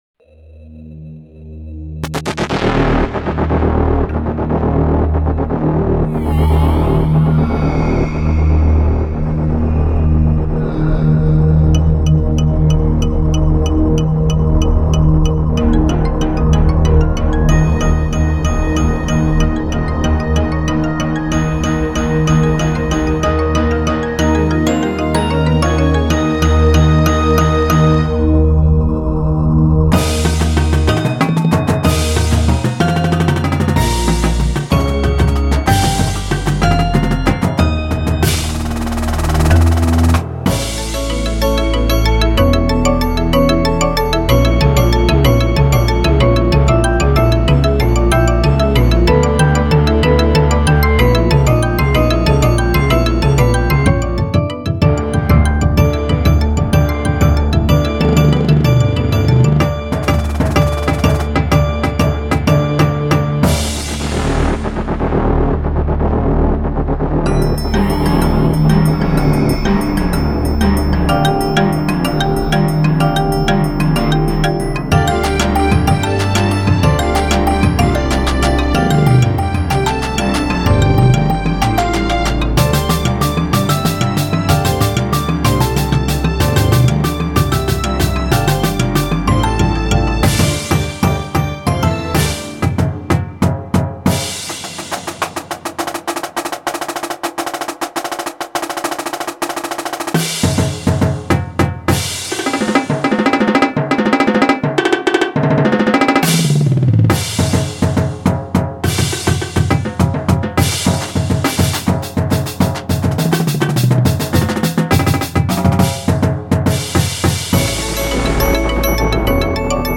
• Snares
• Quads (5 or 6 drums)
• 5 Bass Drums
• Hand Cymbals
• Bells/Chimes
• Xylophone
• 2 Vibraphones
• 2 Marimbas
• Timpani
• Synthesizer (2 Synth Option)
• Bass Guitar